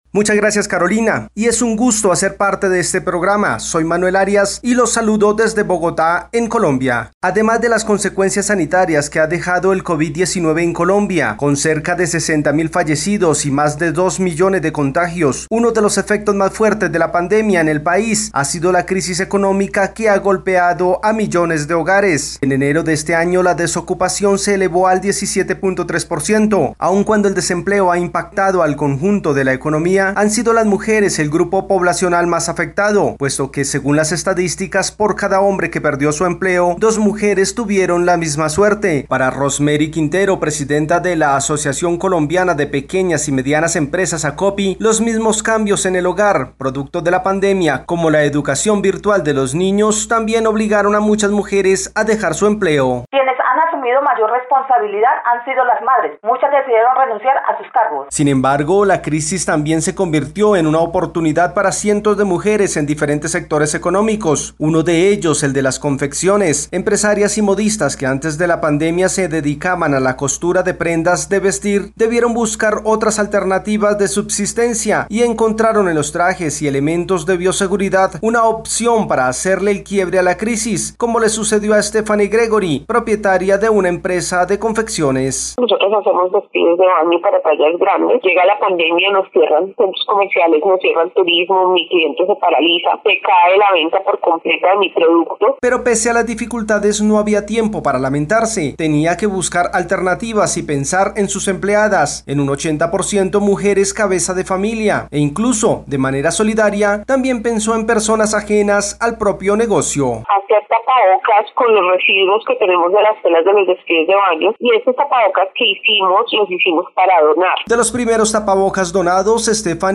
La creatividad, una oportunidad en medio de la pandemia del COVID-19 para las modistas y las diseñadoras colombianas. Reportaje especial